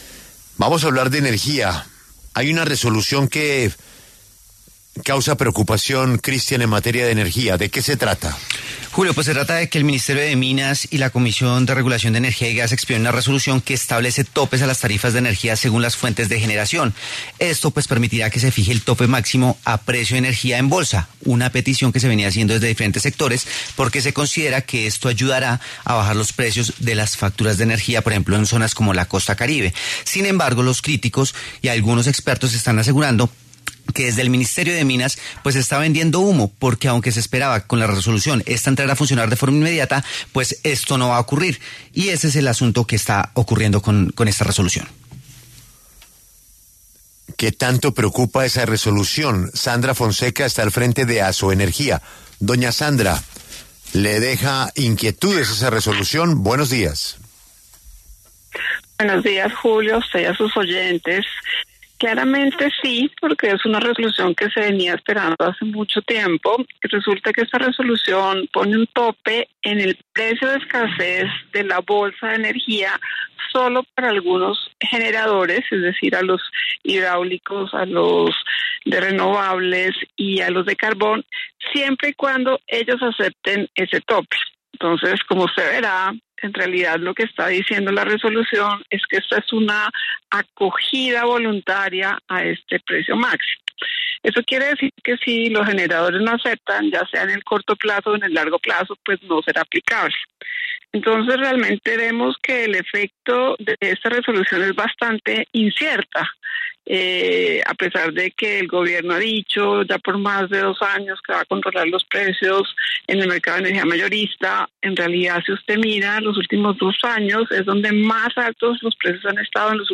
En entrevista con W Radio